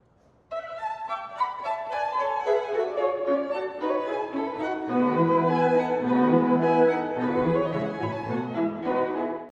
↑古い録音のため聴きづらいかもしれません！（以下同様）
明るく、跳ねるような終楽章です。